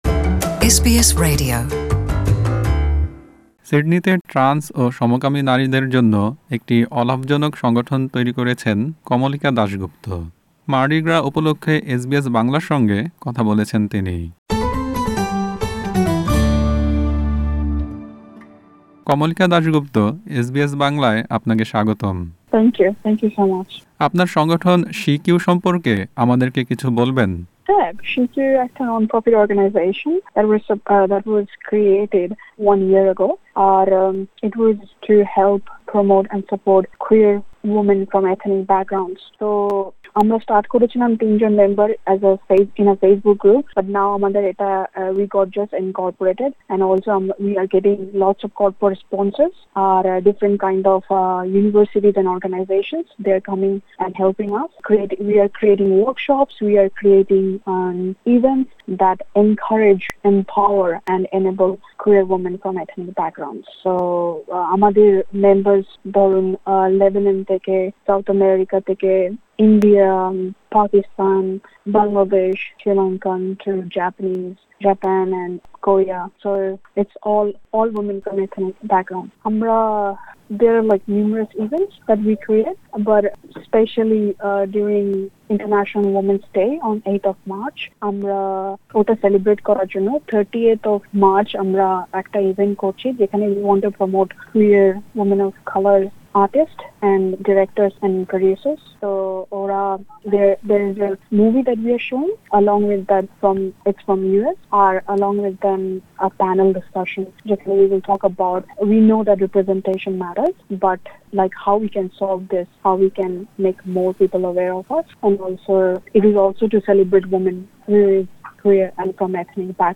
সাক্ষাৎকারটি বাংলায় শুনতে উপরের অডিও প্লেয়ারটিতে ক্লিক করুন।